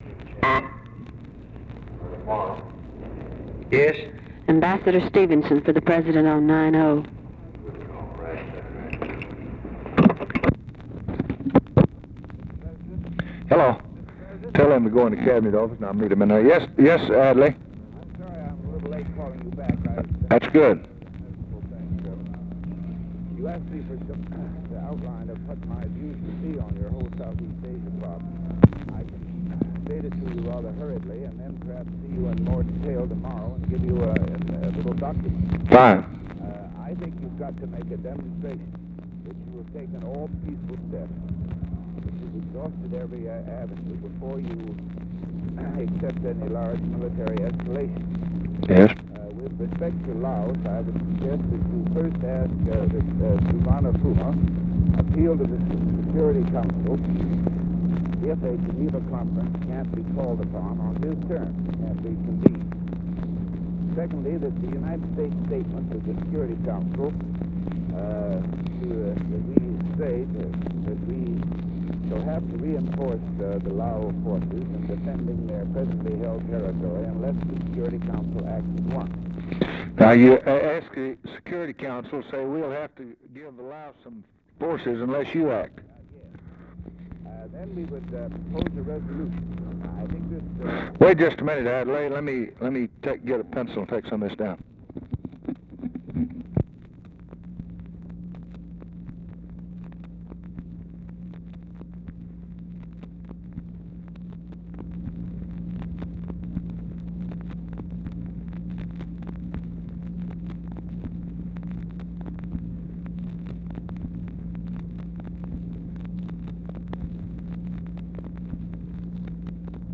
Conversation with ADLAI STEVENSON, May 27, 1964
Secret White House Tapes